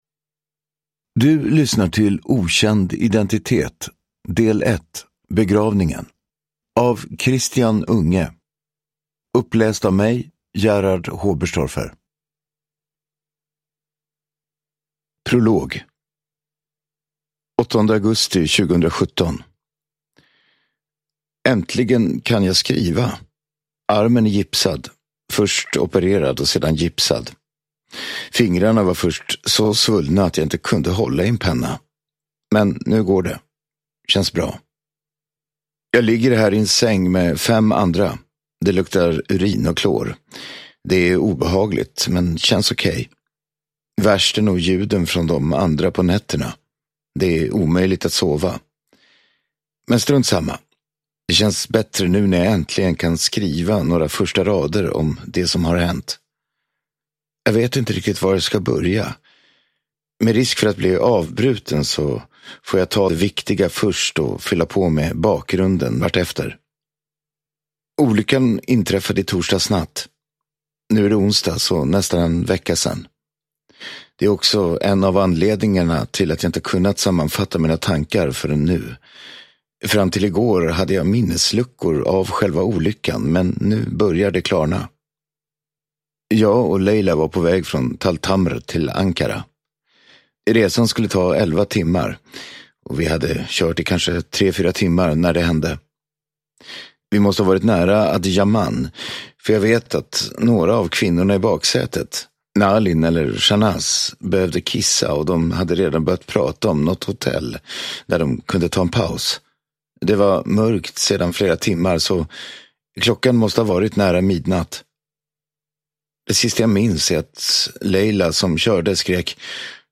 Begravningen (ljudbok) av Christian Unge